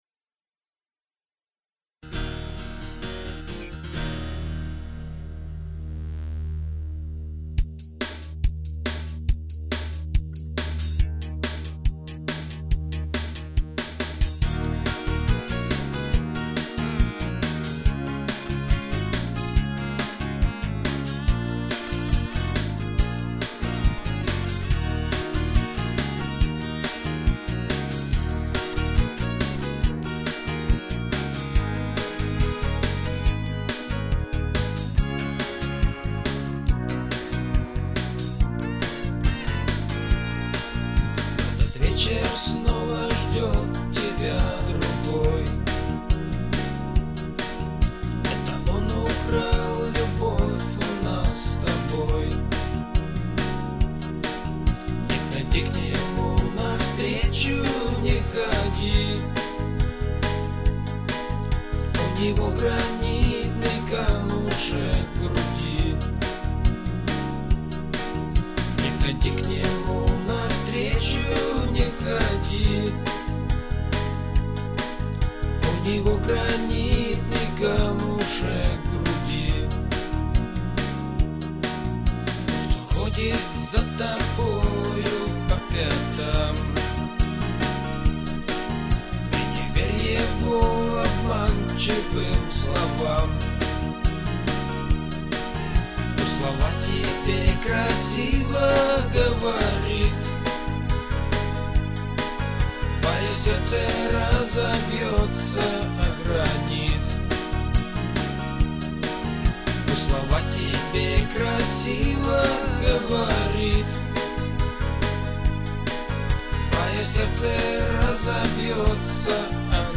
Поп (4932)